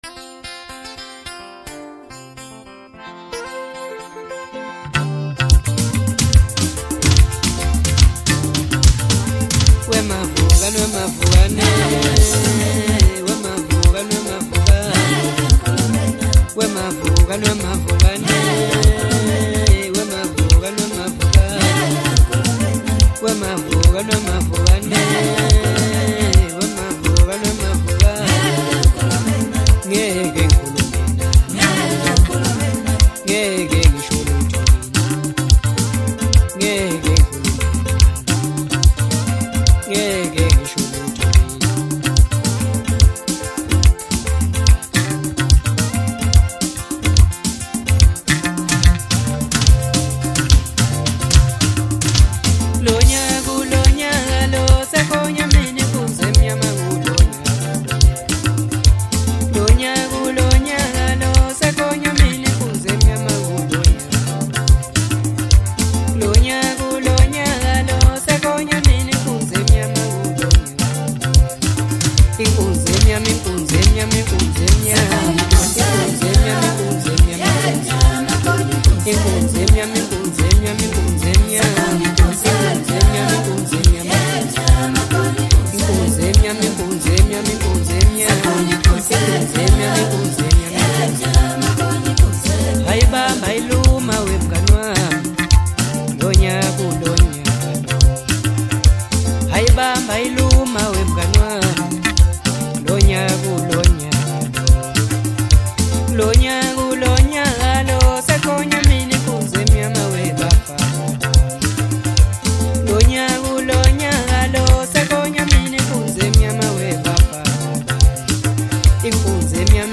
Home » DJ Mix » Hip Hop » Maskandi
South African singer-songwriter